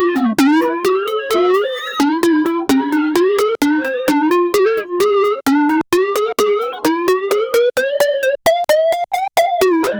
a-fat-girl-farting-ynykdkih.wav